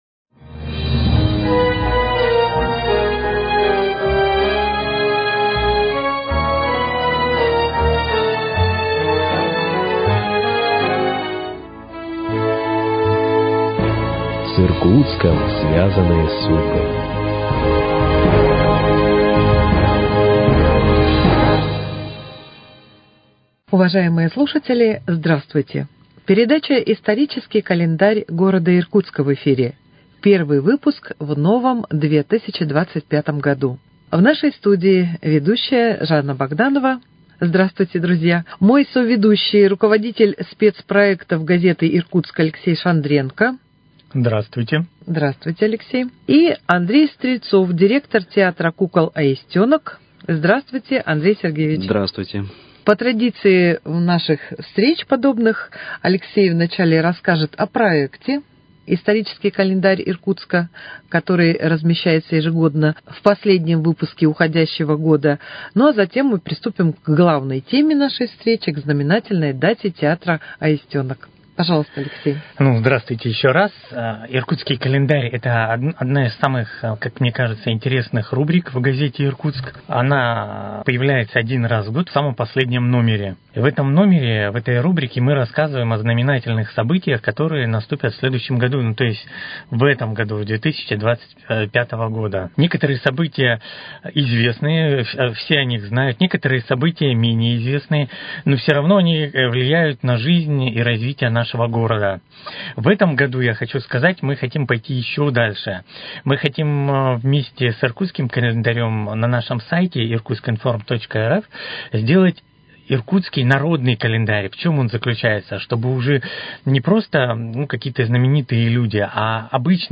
беседовали в студии